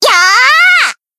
BA_V_Reisa_Battle_Shout_2.ogg